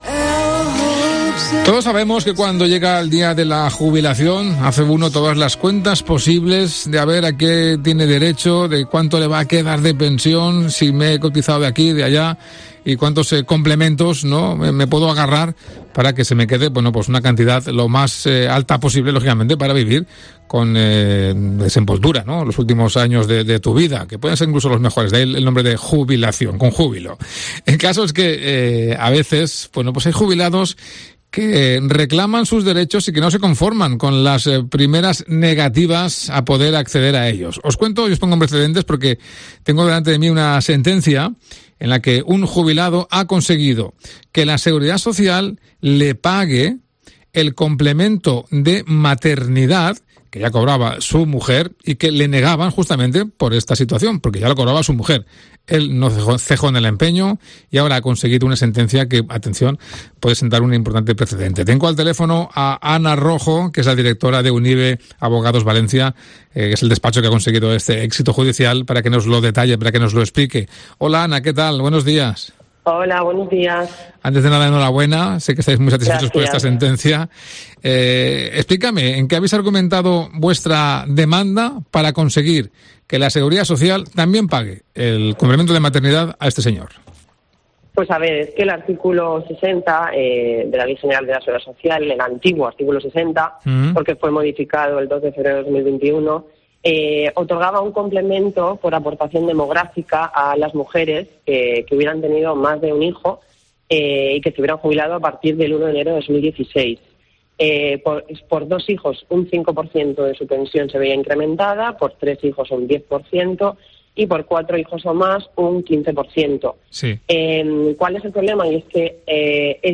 se ha pasado por los micrófonos de Mediodía COPE Más Valencia, para analizar las claves de la sentencia